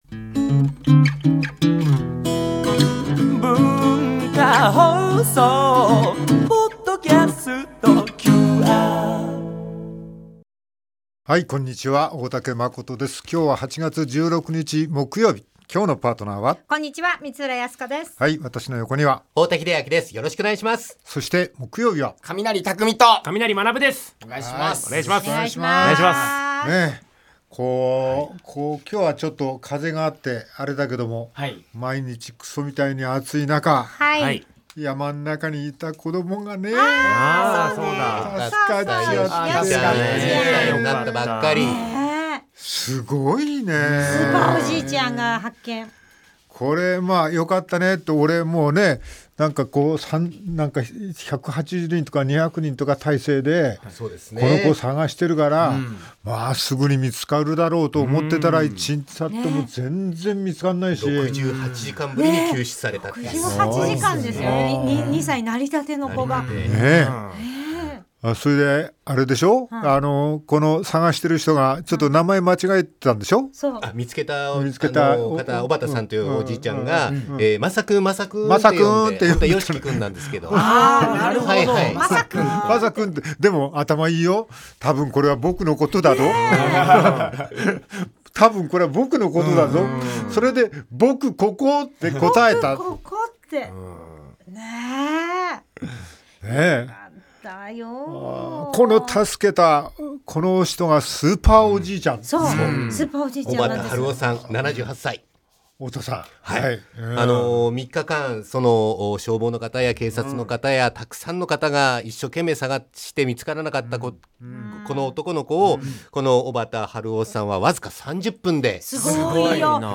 大竹まことさんとパートナーとのオープニングトークをお楽しみください！！